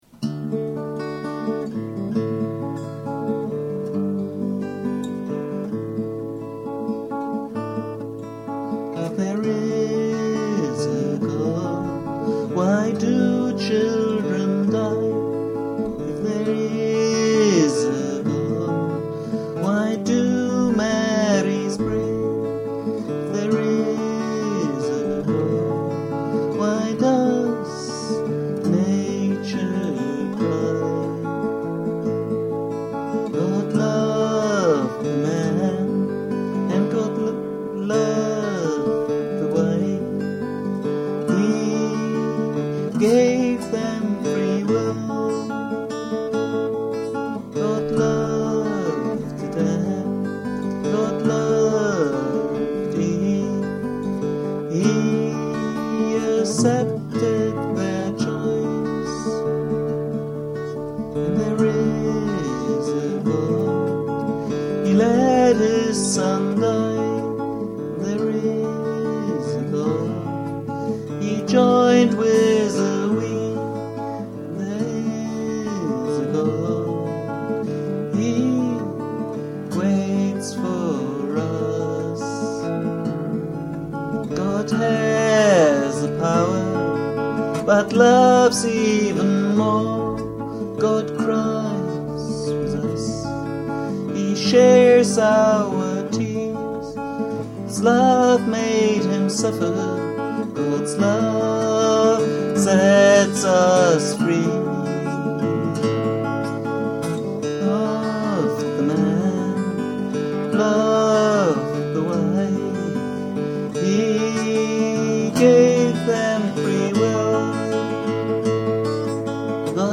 The text was written after one of these discussions, why God allows all this suffering. Under the influence of Martyn Wyndham-Read, it became my first tune in Drop-D.